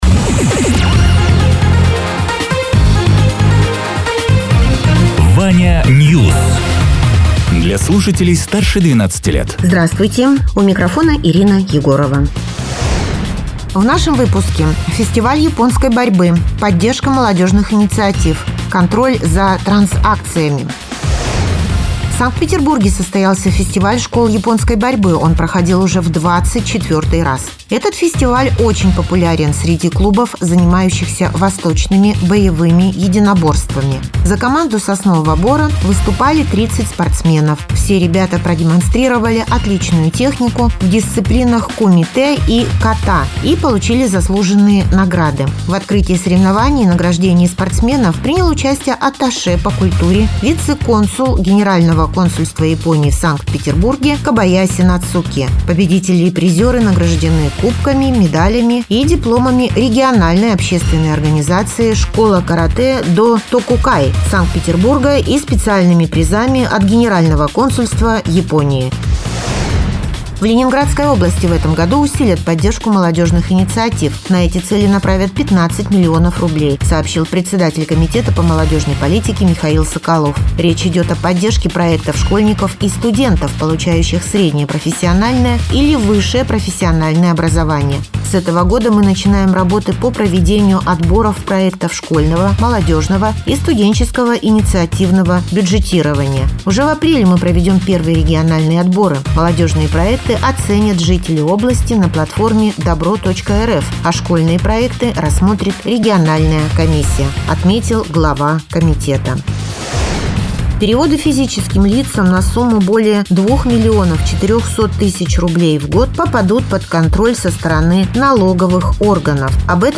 Радио ТЕРА 14.04.2026_08.00_Новости_Соснового_Бора